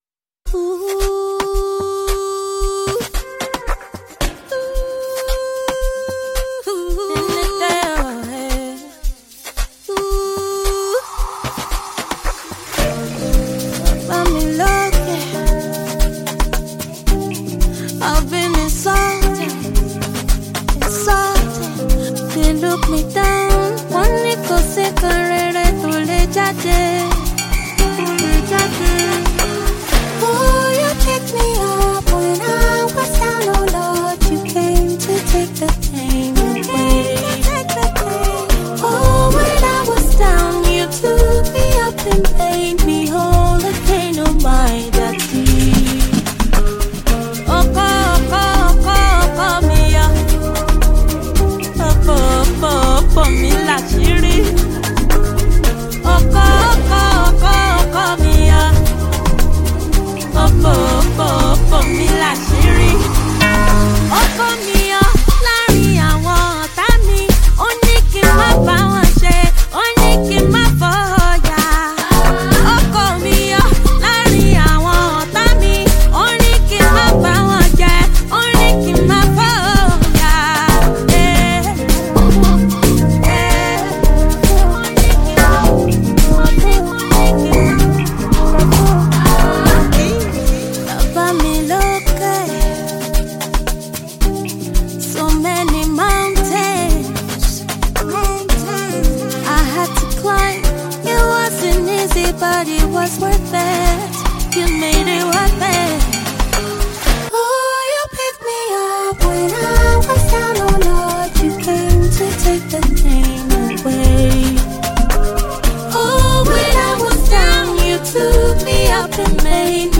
With its infectious rhythms and captivating melodies